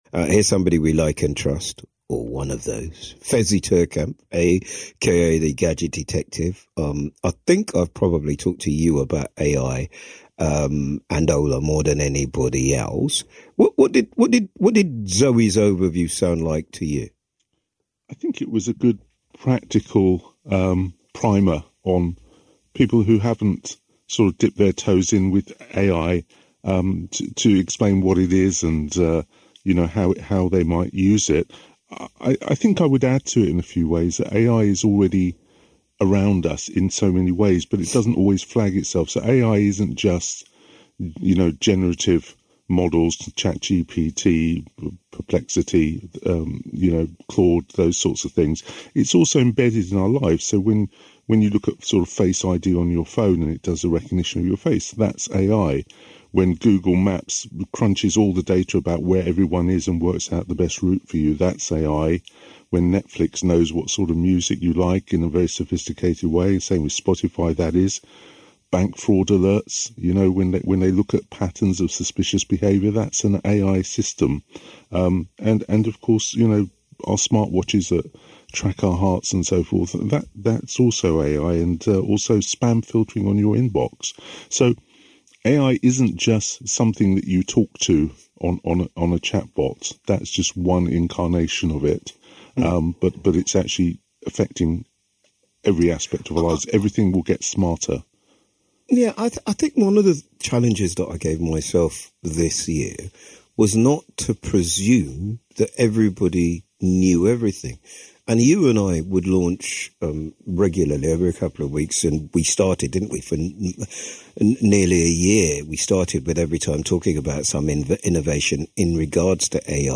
joins Eddie Nestor on BBC Radio London to discuss generative AI.